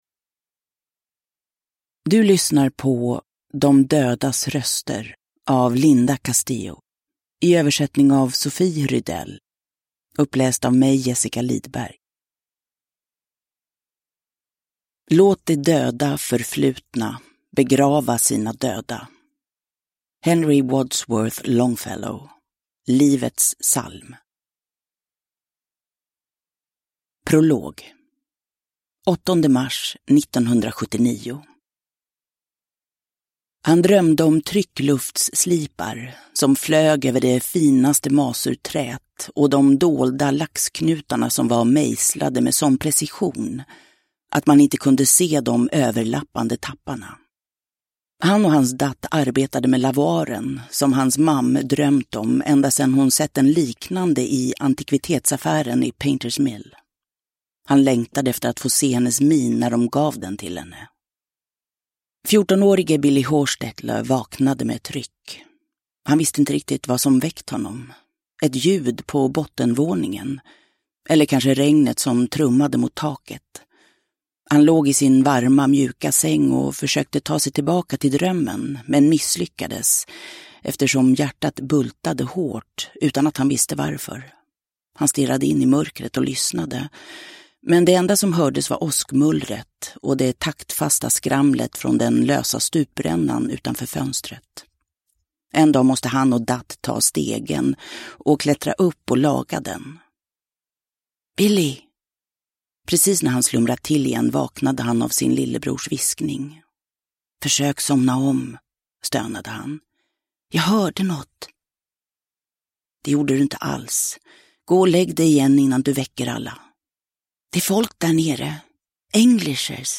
Uppläsare: Jessica Liedberg
Ljudbok